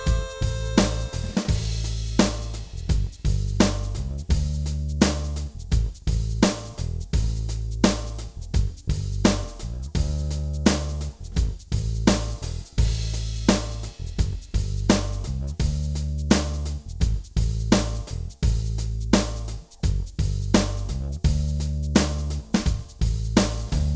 Minus All Guitars For Guitarists 4:39 Buy £1.50